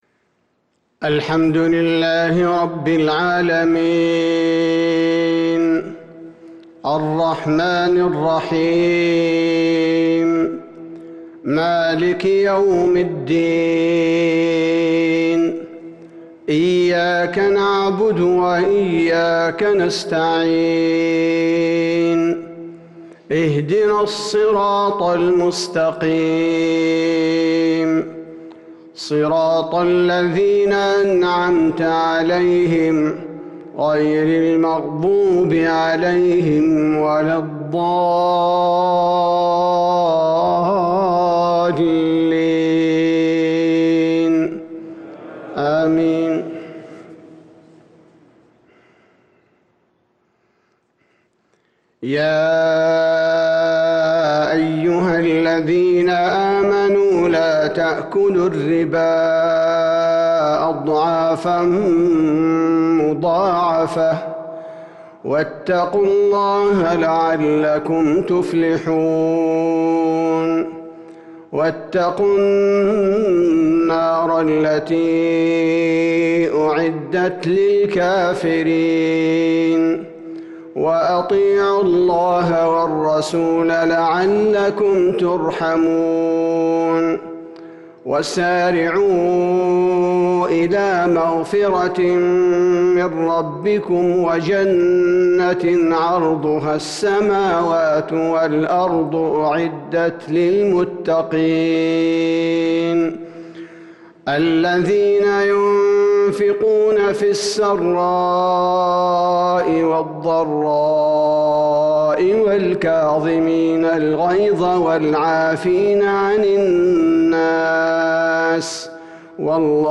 عشاء ٤-٧- ١٤٤٣هـ سورتي آل عمران والتوبة | Isha prayer from Surah Al-Imran & at-Taubah 5-2-2022 > 1443 🕌 > الفروض - تلاوات الحرمين